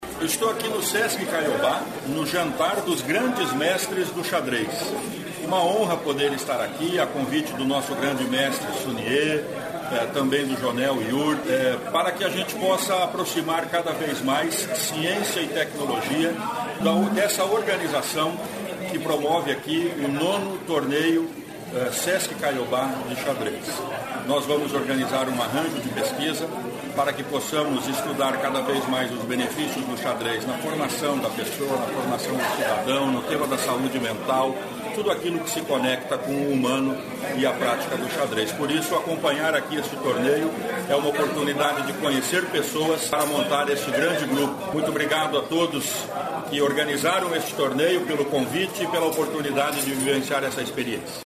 Sonora do secretário da Ciência, Tecnologia e Ensino Superior, Aldo Nelson Bona, sobre o torneio internacional de xadrez